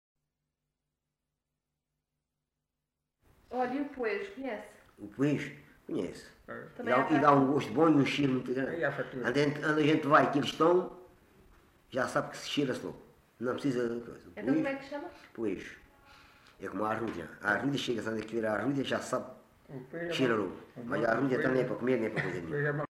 LocalidadeAlte (Loulé, Faro)